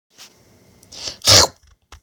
Chomp!